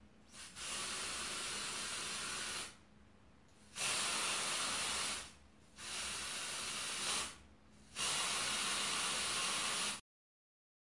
消毒剂罐 - 声音 - 淘声网 - 免费音效素材资源|视频游戏配乐下载
可以喷洒气溶胶除臭剂。 采用Samson R21S，Stereo，.WAV，48KHZ 24bit录制。